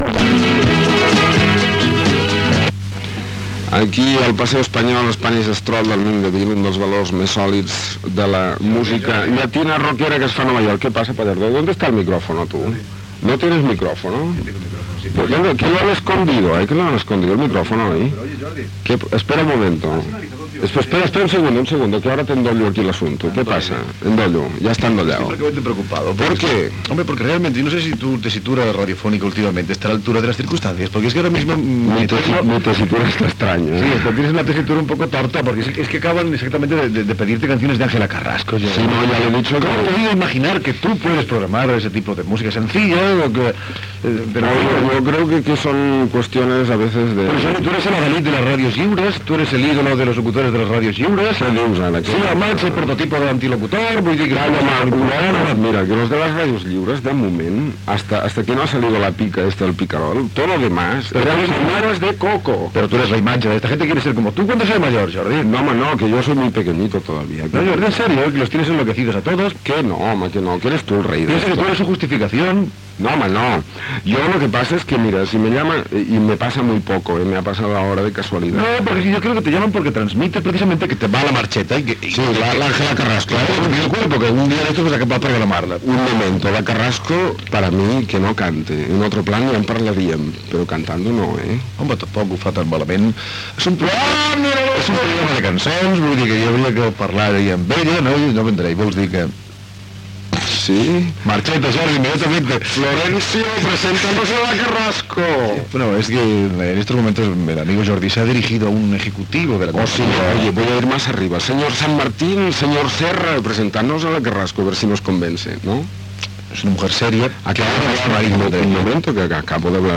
Diàleg entre els presentadors sobre els temes musicals que es programen, les ràdios lliures, com Ràdio Pica, la cantant Ángela Carrasco, la venda del segell discogràfic Fania i tema musical
Entreteniment